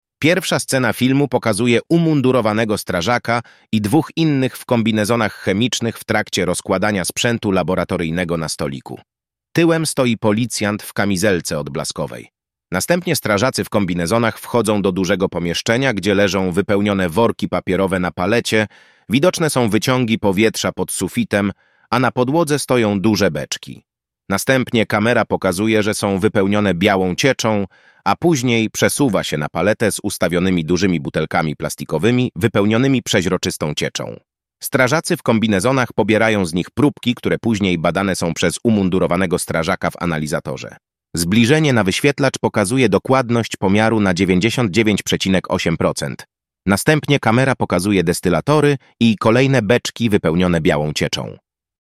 Nagranie audio Audiodeskrypcja_filmu.mp3